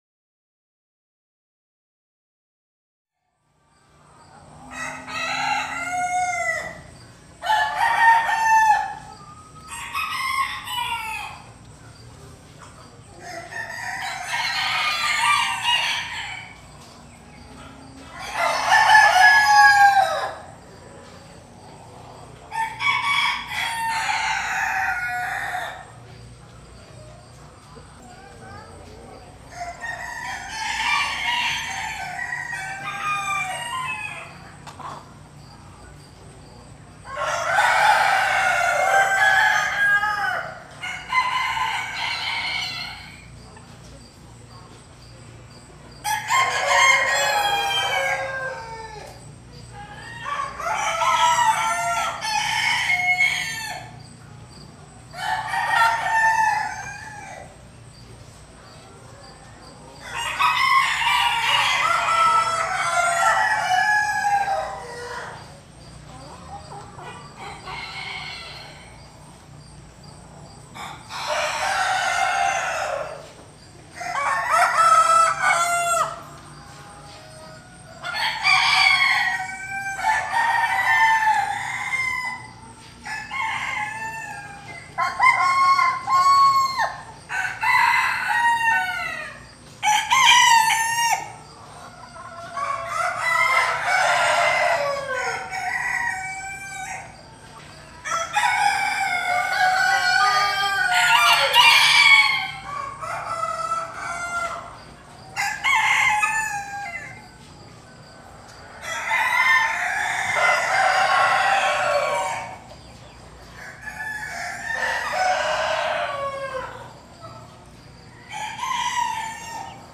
17:56 MP3 This recording was made over a two day period at the Jo Daviess County Fair in rural Illinois – the heartland of the United States. Competitions are held for the best breed of poultry, lamb, beef, rabbit, and waterfowl.